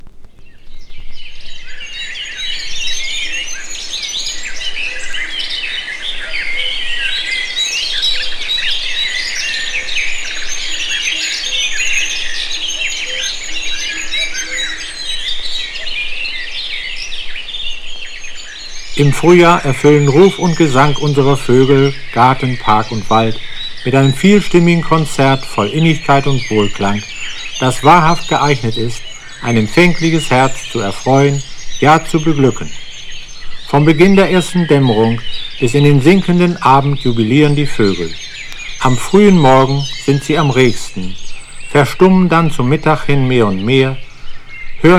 彼の研究の一端を資料化、本人の解説がとベルリンやミュンヘンでフィールドレコーディングして採集された様々な野鳥の声が収録された1枚。【収録鳥】モリフクロウ、ナイチンゲール、クロジョウビタキ、シロビタイジョウ、ロビン、クロウタドリ、ウタツグミ、シジュウカラ、ズアオアトリ、ミソサザイとカッコウ、キツツキ、モリムシクイ、ニワムシクイ、ブラックキャップ、ブラックキャッチャー、ゴールデンオリオール、ゴールデンハンマー、マダラヒタキ、イエローモッカー、ハシボソガラス、ヨーロッパビンズイ、ヤツガシラ、グリーンキツツキ
Other, Non-Music, Field Recording　Germany　12inchレコード　33rpm　Stereo